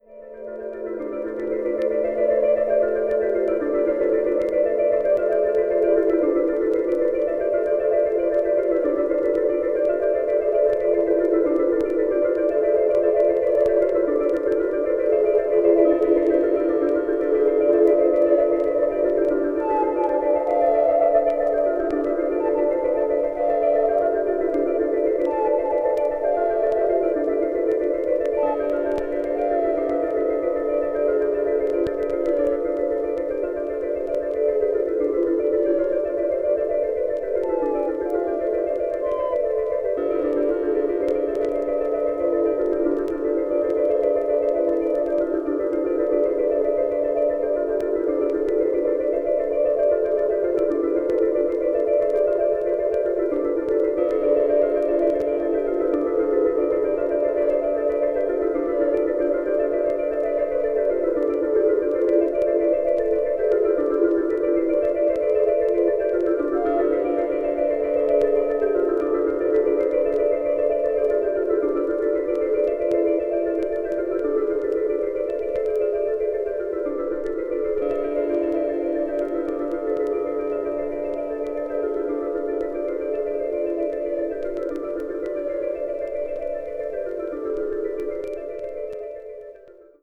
media : EX/EX(わずかにチリノイズが入る箇所あり)
地下のマグマが沸騰しているような、あるいは地球が振動しているような、深い瞑想的な世界です。